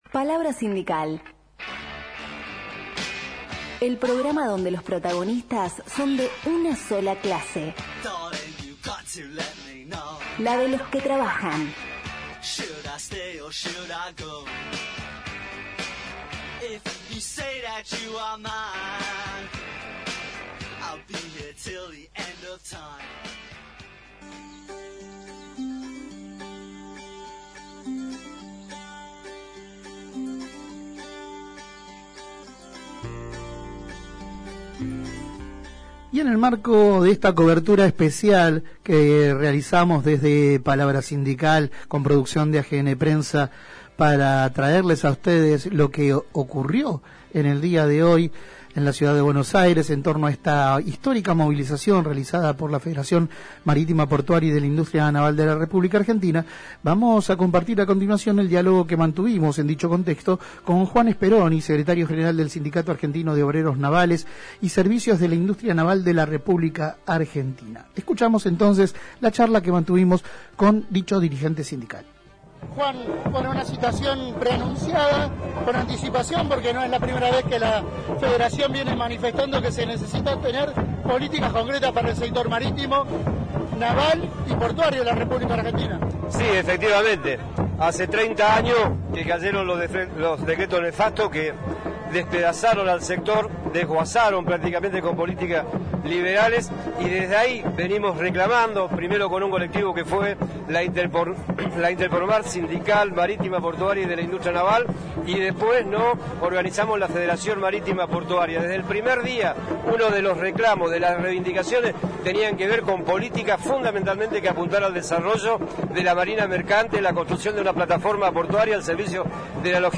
Compartimos la entrevista completa: